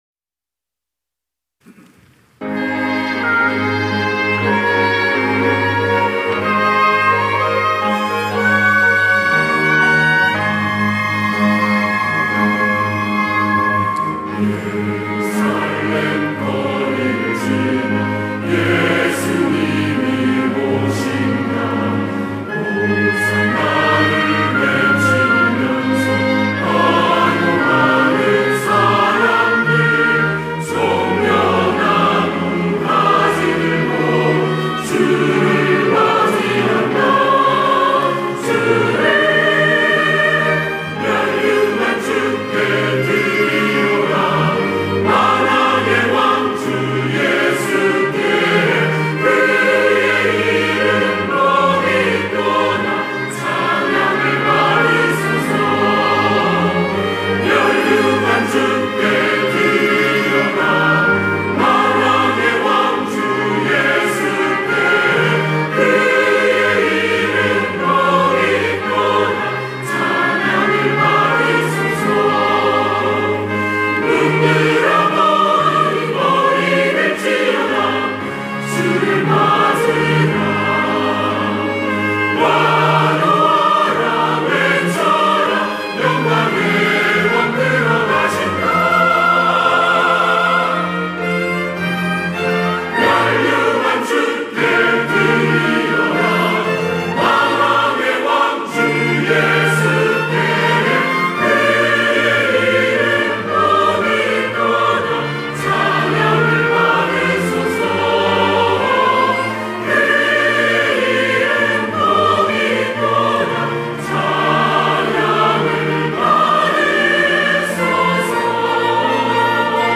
할렐루야(주일2부) - 면류관 드리세 호산나
찬양대